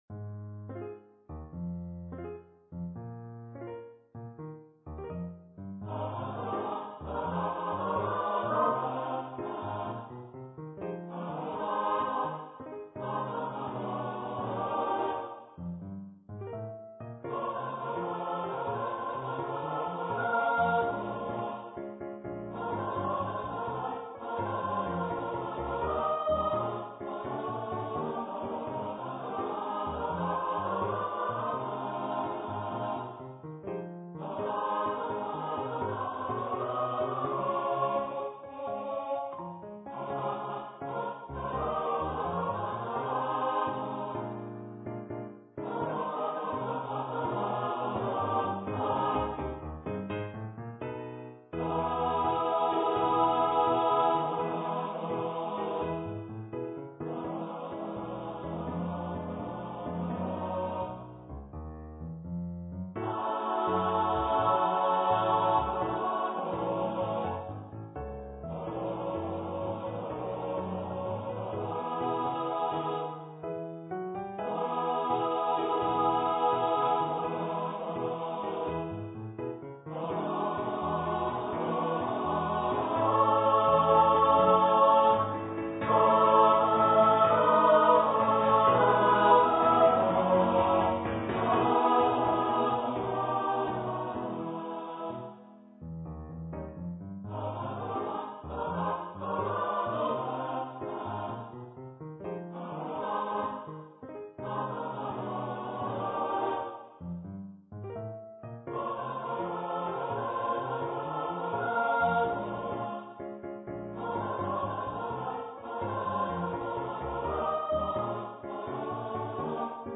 for SA+Men choir and Piano
An original light-hearted song of celebration.